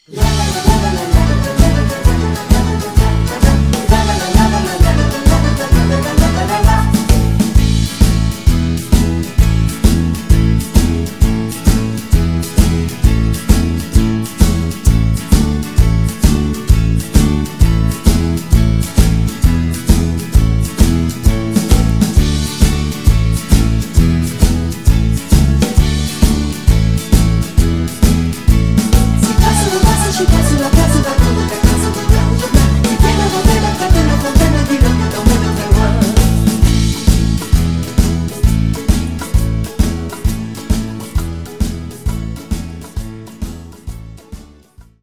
live in Bastia